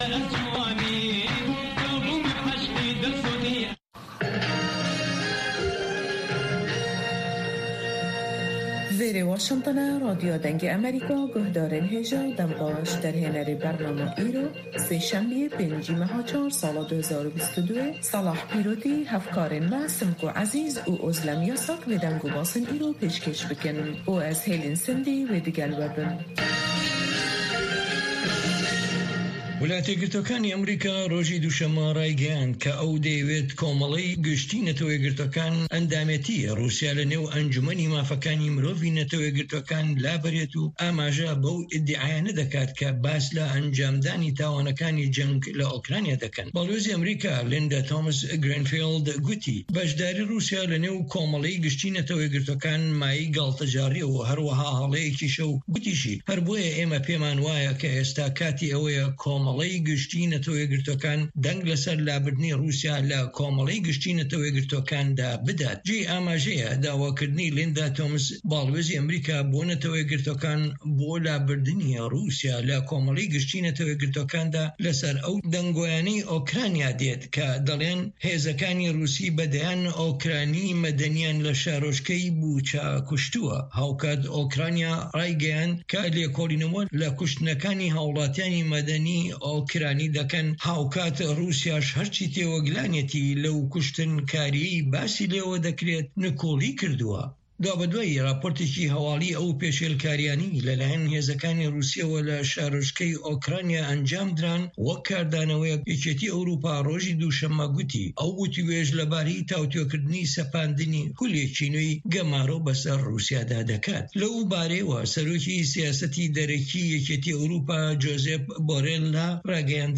هه‌واڵه‌کان، ڕاپـۆرت، وتووێژ